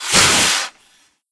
c_wserpent_atk1.wav